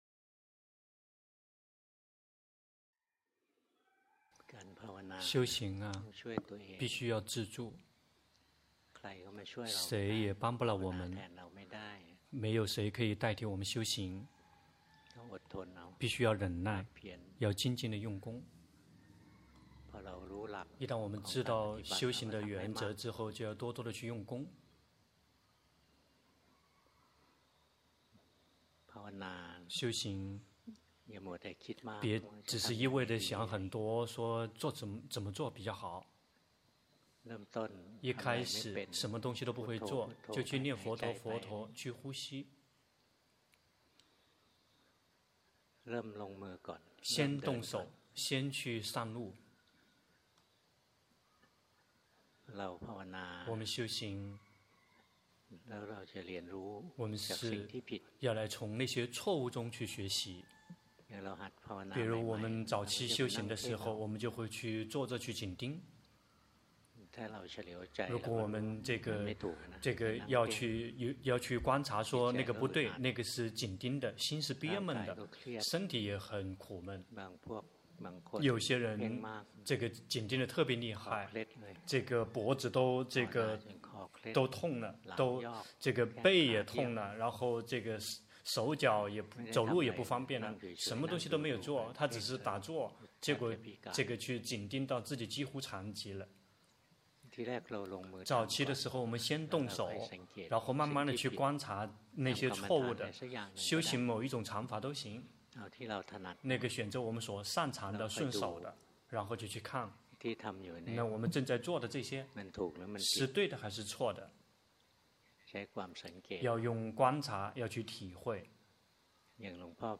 2021年02月07日｜泰國解脫園寺 同聲翻譯